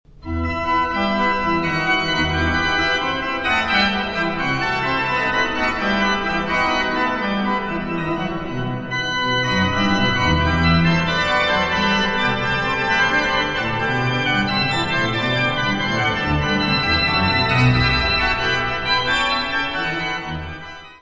Organ works
Notas:  Este disco foi gravado ao vivo na Basilica de St. Alexander und Theodor, Benediktinerabtei em Ottobeuren na Alemanha, durante o mês de Maio de 1998; O orgão utilizado na gravação foi construído por Johann Andreas Silbermann, entre 1756-1761; No do Serviço de Aquisições e Tratamento Técnico